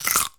crunch05.wav